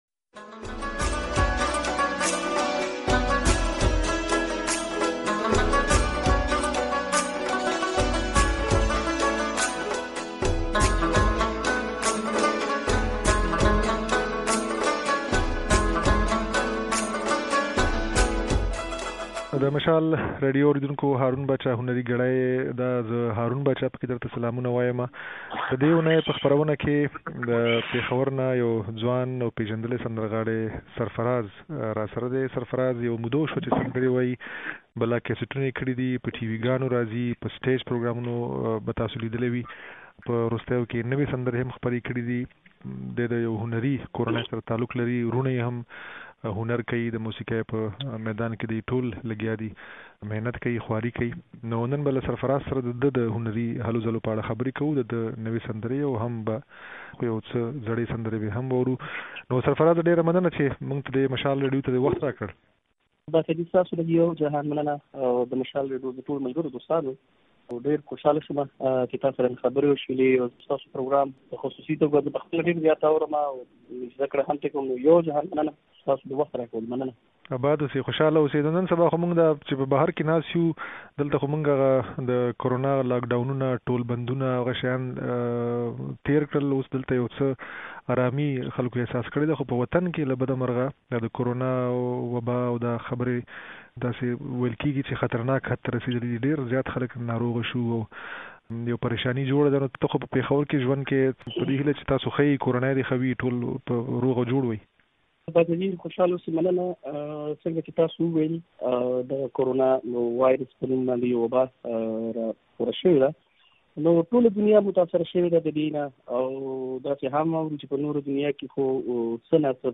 د نوموړي دا خبرې او څو سندرې يې د غږ په ځای کې اورېدای شئ.